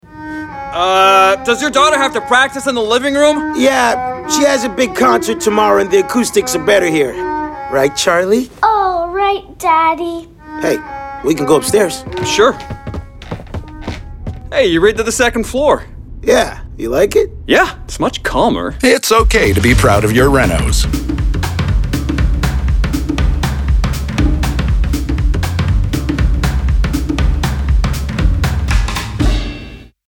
Publicité (RONA) - ANG